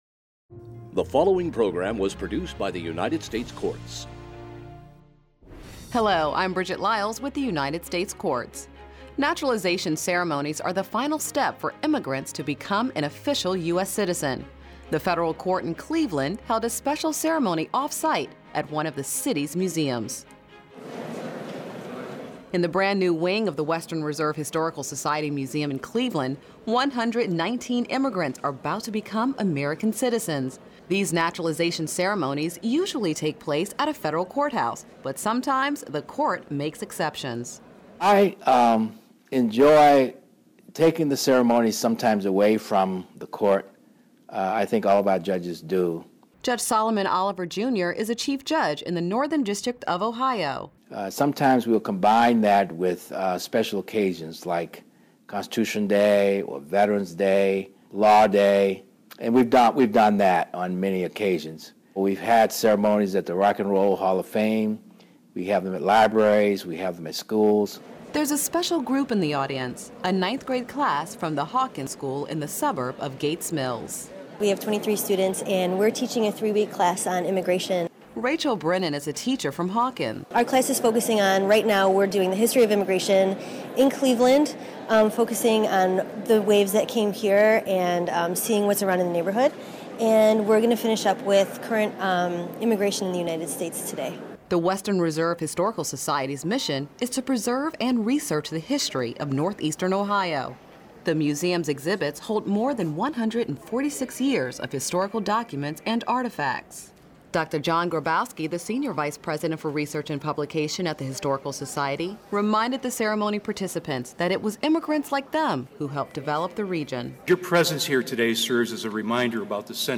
Cleveland Museum Naturalization Ceremony
A federal judge from the Northern District of Ohio held a recent naturalization ceremony at a Cleveland history museum, and in the process created a memorable event for two groups.